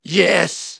synthetic-wakewords
ovos-tts-plugin-deepponies_Demoman_en.wav